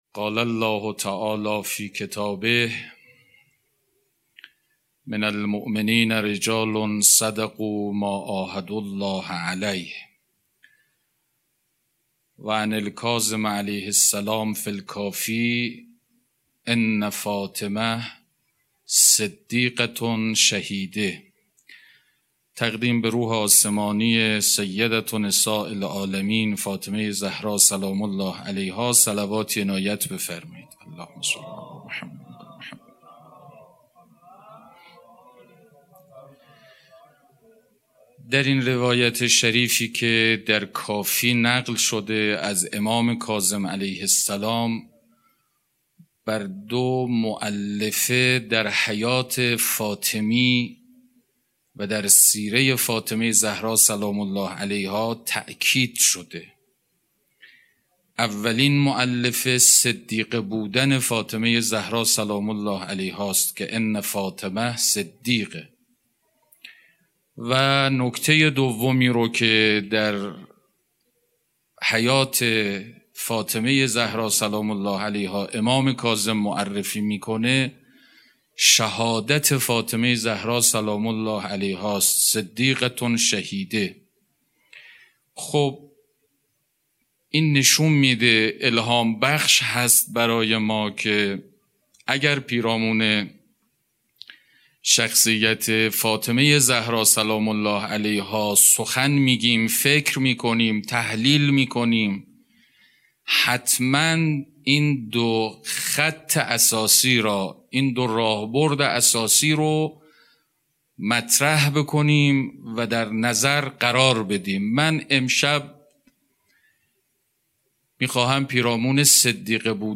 سخنرانی: صدیقه، مؤلفه اصلی سیره حضرت زهرا(س)